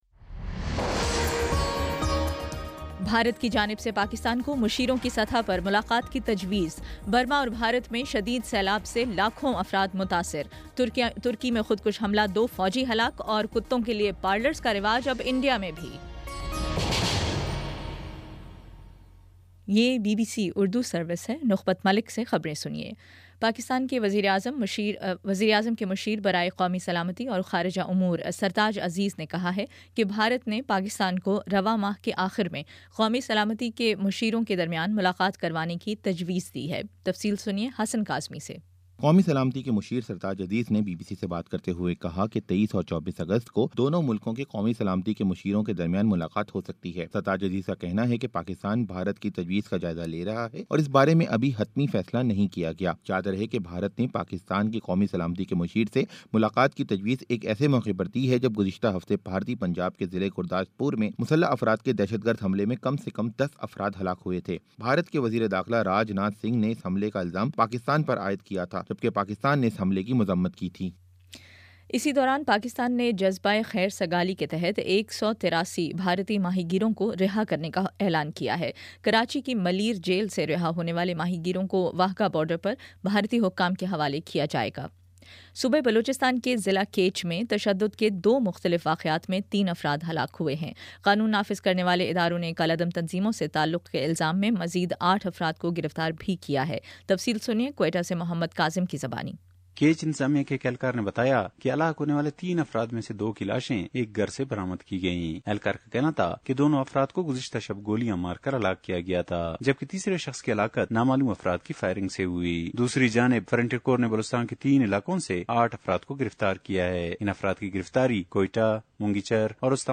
اگست 02: شام پانچ بجے کا نیوز بُلیٹن